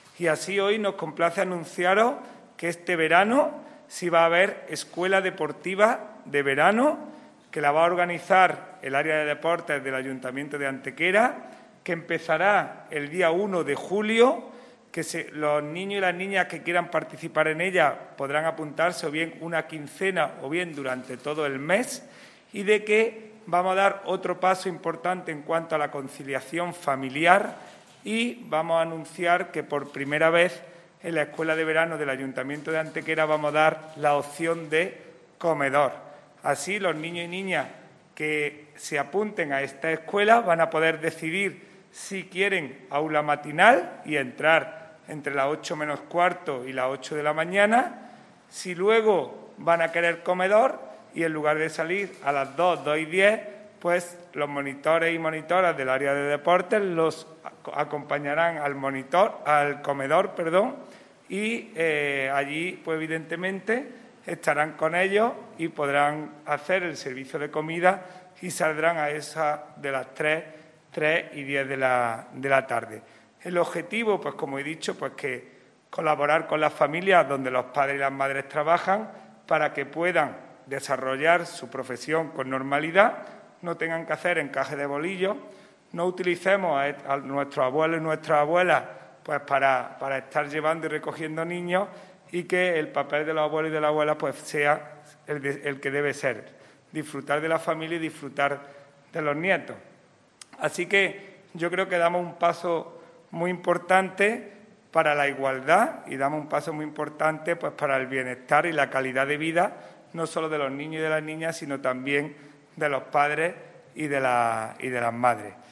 El teniente de alcalde delegado de Deportes, Juan Rosas, ha presentado hoy en rueda de prensa la Escuela Deportiva de Verano 2021, iniciativa que promueve el Área de Deportes del Ayuntamiento de Antequera con el objetivo de facilitar la conciliación familiar y laboral durante el mes de julio, época ya de vacaciones en los colegios.
Cortes de voz